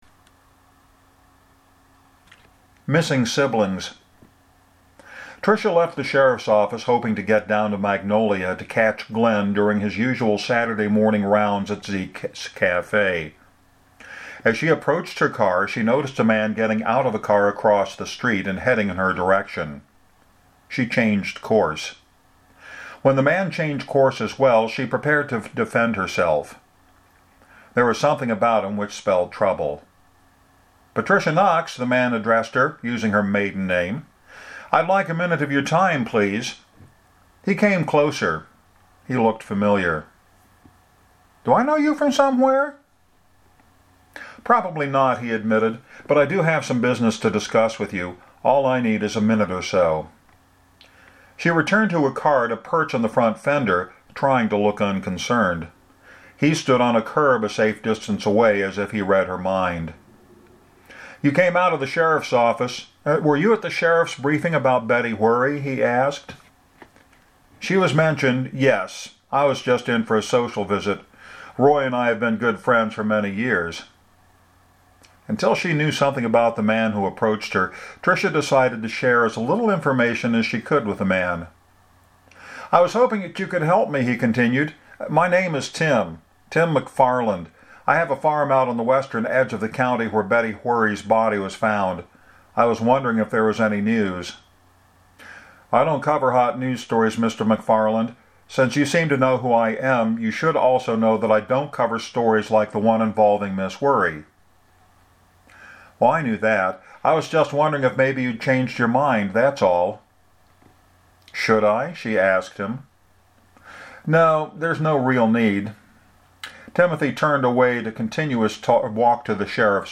Here’s the first Monday installment of my regular reading schedule.